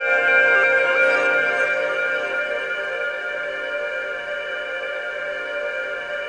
DM PAD1-21.wav